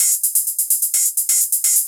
Index of /musicradar/ultimate-hihat-samples/128bpm
UHH_ElectroHatB_128-01.wav